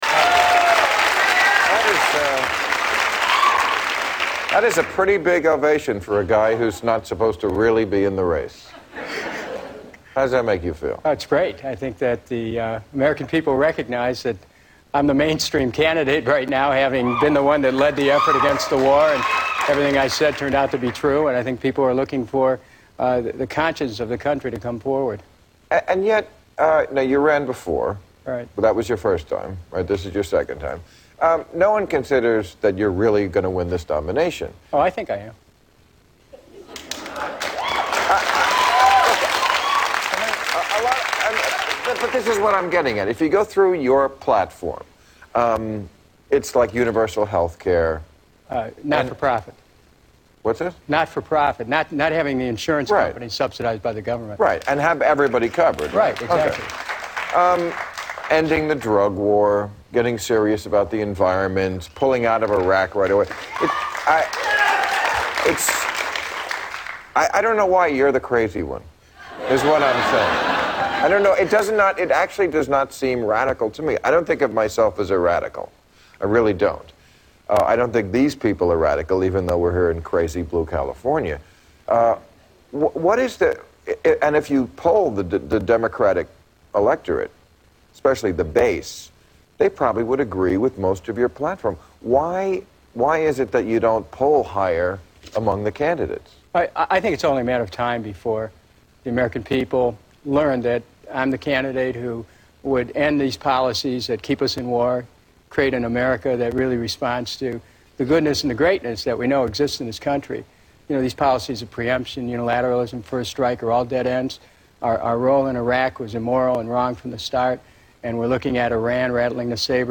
Kucinich's Interview with Bill Maher